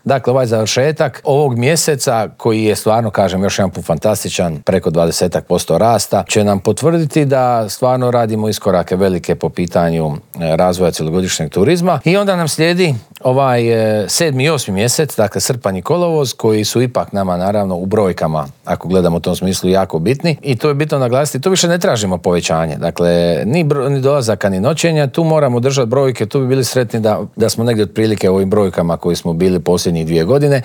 O dosad ostvarenim rezultatima, očekivanjima od ljetne turističke sezone, ali i o cijenama smještaja te ugostiteljskih usluga razgovarali smo u Intervjuu tjedna Media servisa s ministriom turizma i sporta Tončijem Glavinom.